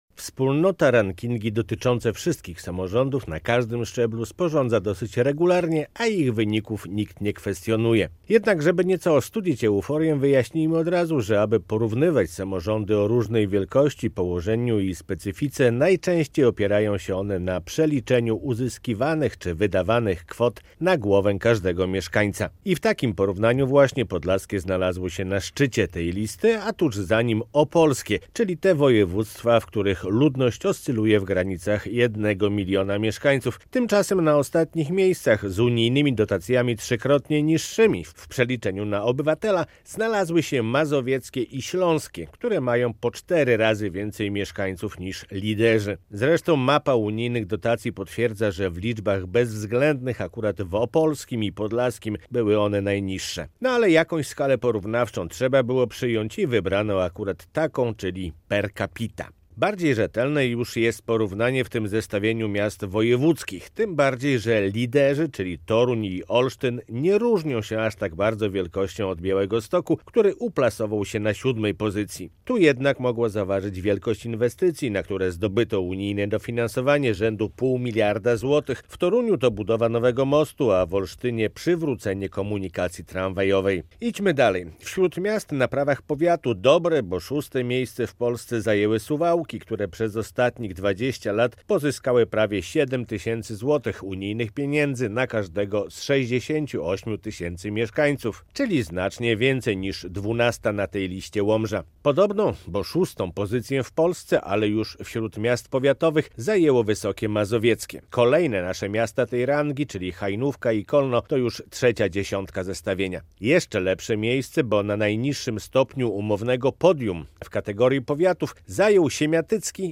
Radio Białystok | Felieton